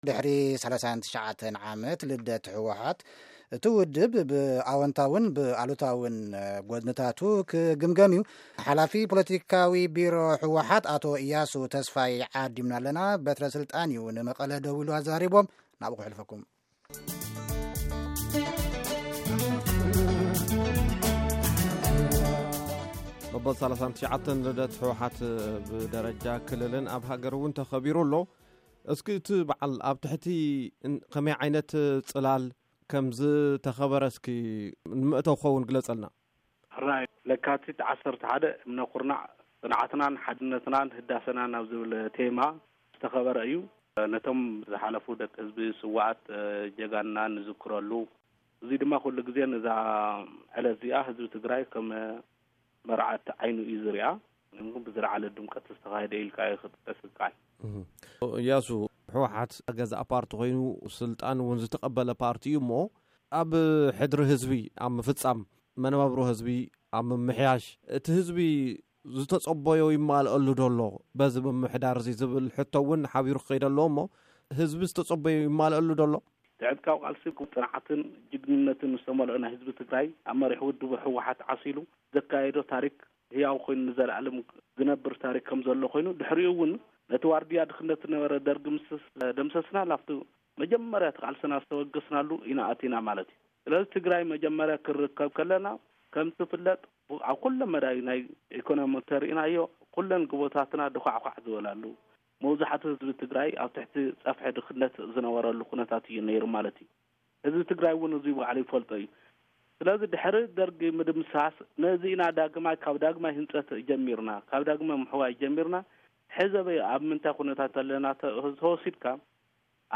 ሙሉእ ቃለ-ምልል